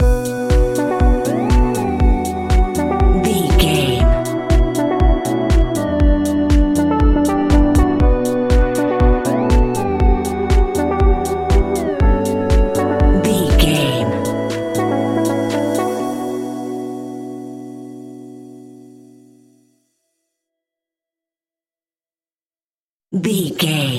Aeolian/Minor
funky
groovy
uplifting
driving
energetic
drum machine
synthesiser
electric piano
house
electro house
funky house
instrumentals
synth bass